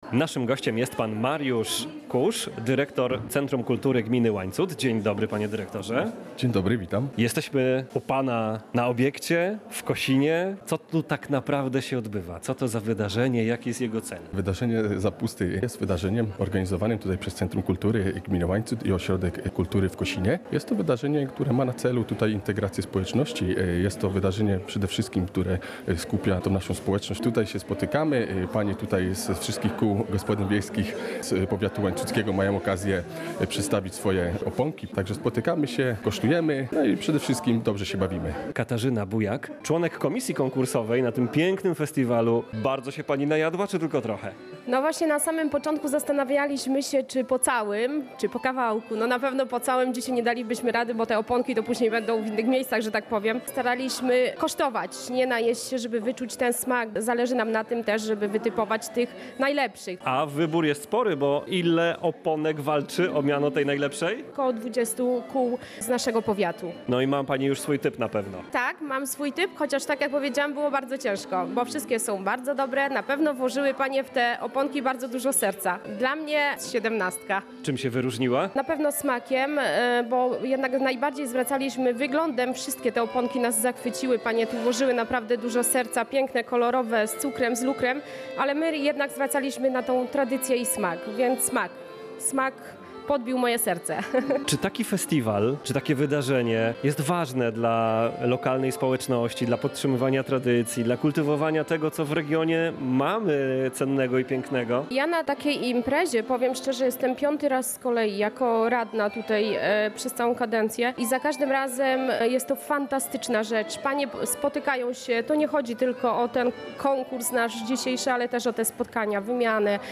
W sobotę 10 lutego w Ośrodku Kultury w Kosinie odbył się Festiwal Oponki. Wzięło w nim udział blisko 20 kół gospodyń wiejskich z powiatu.
Relacja